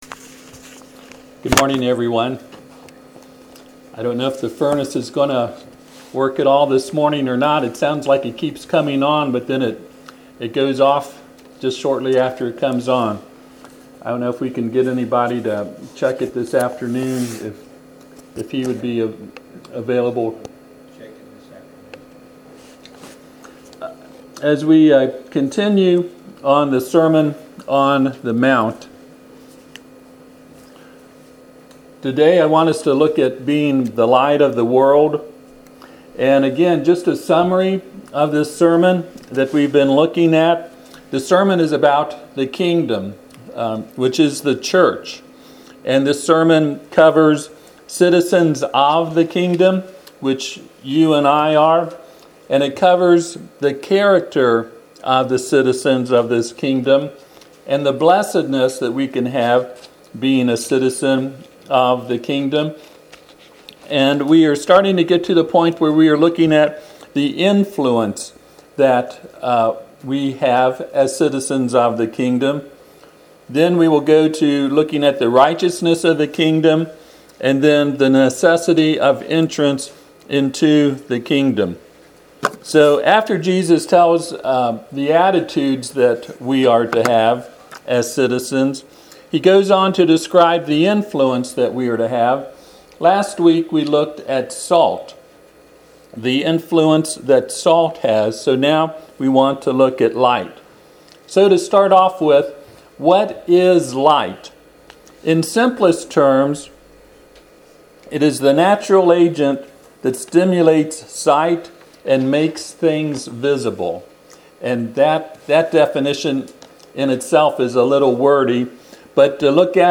Passage: Matthew 5:13-16 Service Type: Sunday AM